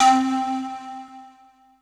D3FLUTE83#02.wav